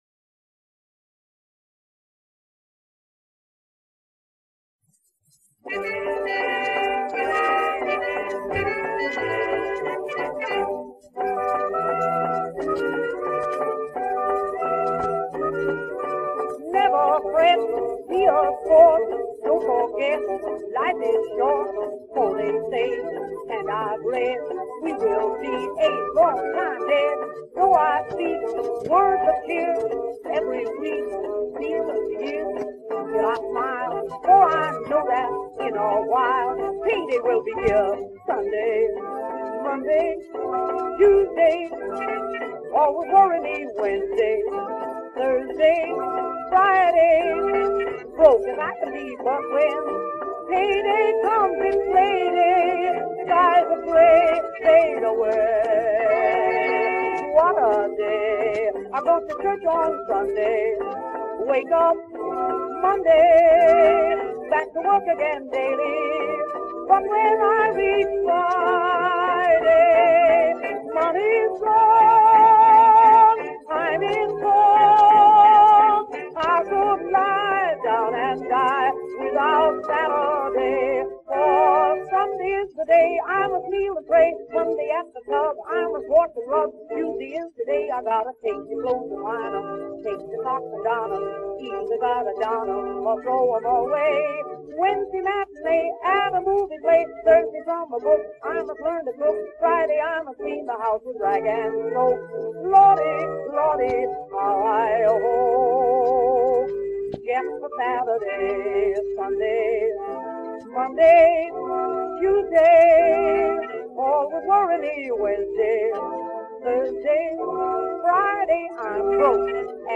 博したUSAの歌手兼ボードビル パフォーマー